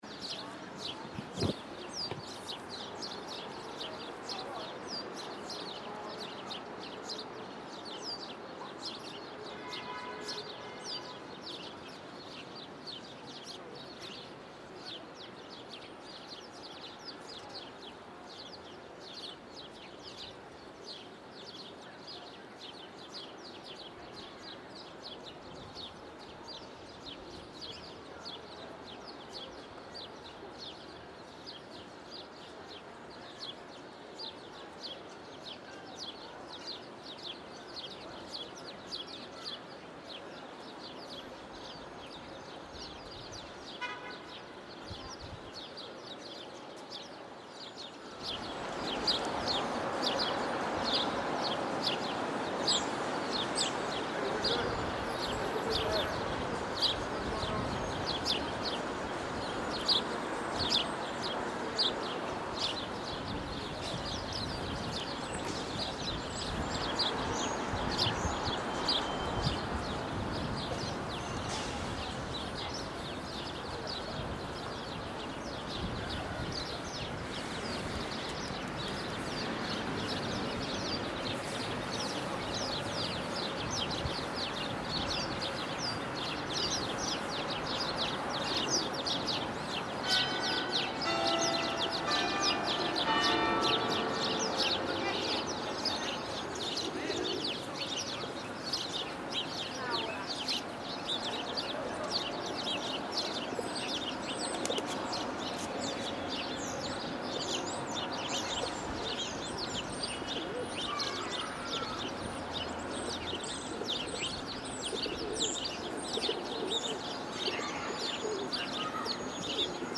Hundreds of sparrows in the trees at Boston Common, their songs intermingling with passing traffic, the chatter of passers-by and competition i the form of ground-wandering pigeons. We hear the bells of the famous Park Street Church chiming midway through the recording.